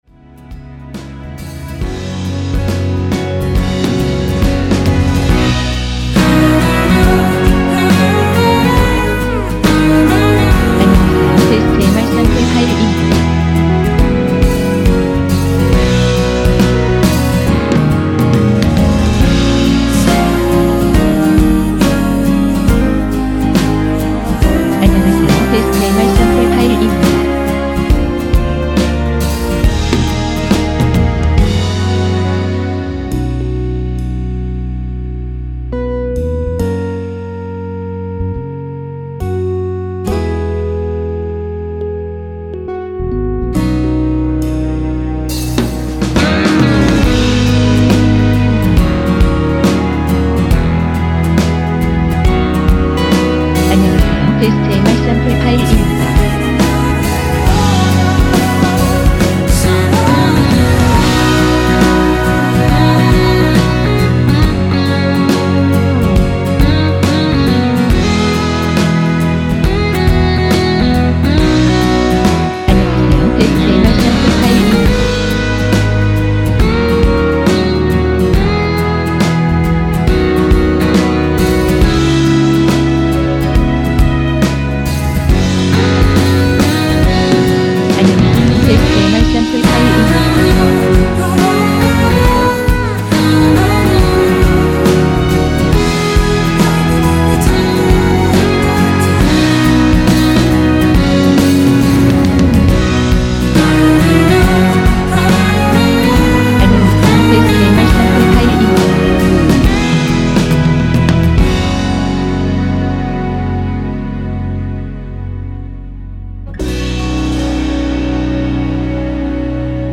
전주 없이 시작하는 곡이라 전주 1마디 만들어 놓았습니다.(일반 MR 미리듣기 참조)
미리듣기에 나오는 부분이 코러스 추가된 부분 입니다.(미리듣기 샘플 참조)
원키에서(-2)내린 코러스 포함된 MR입니다.
Ab
앞부분30초, 뒷부분30초씩 편집해서 올려 드리고 있습니다.